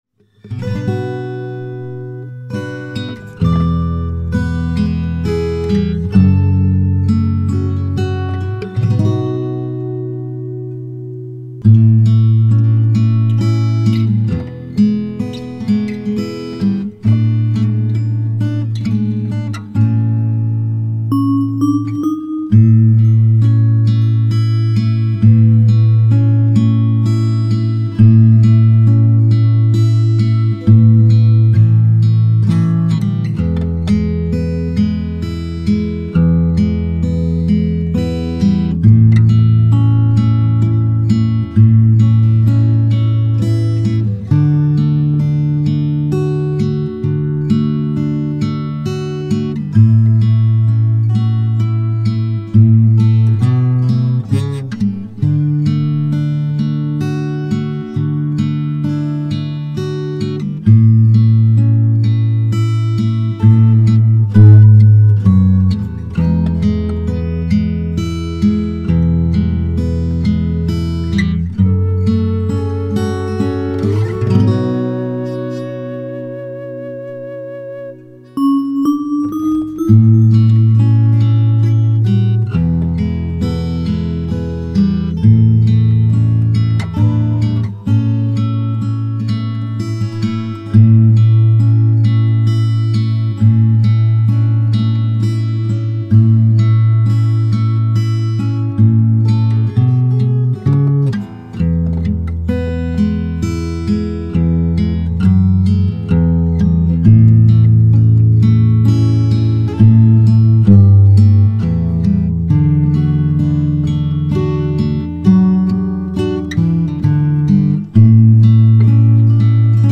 Kolędy
gitara) Cicha noc
Cicha-noc-gitara.mp3